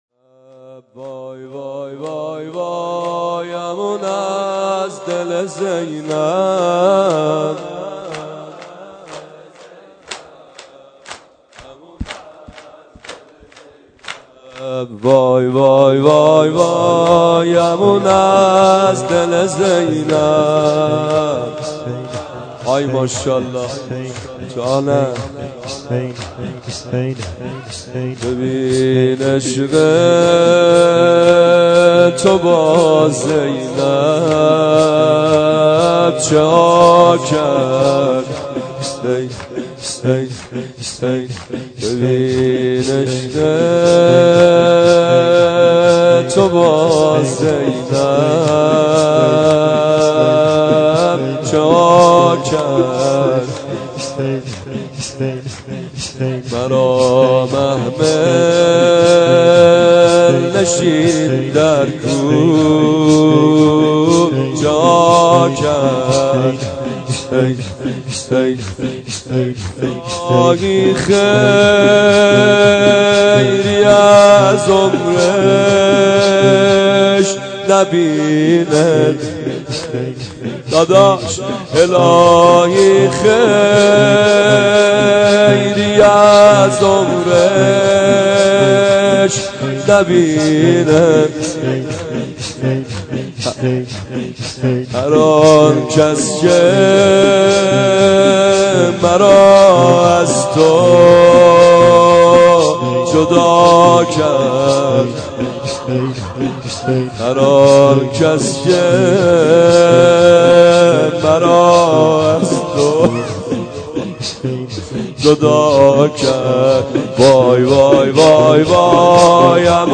زمینه | امان از دل زینب